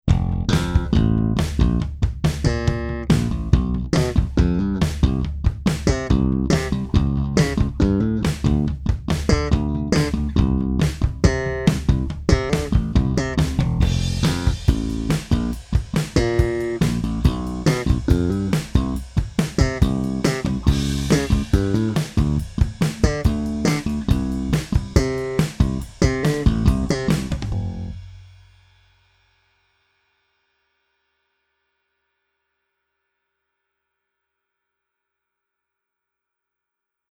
dry-bez kompresoru:
Slap - dry